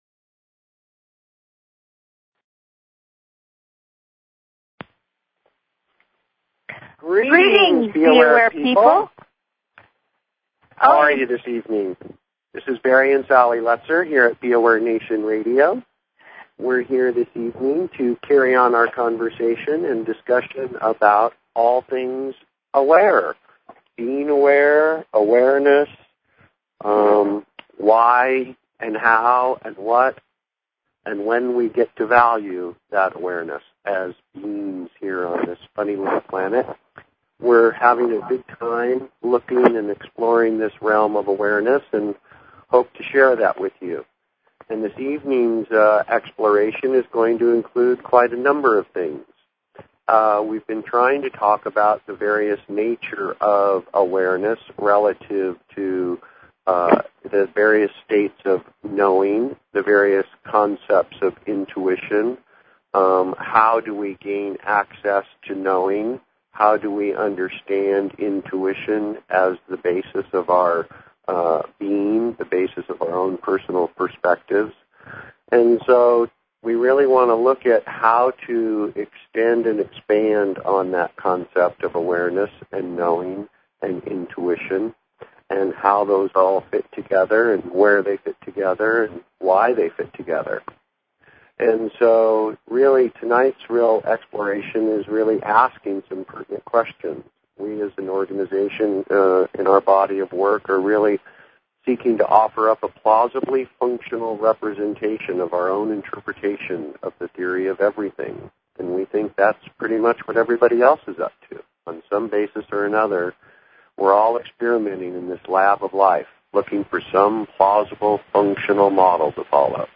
Talk Show Episode, Audio Podcast, B_Aware_Nation and Courtesy of BBS Radio on , show guests , about , categorized as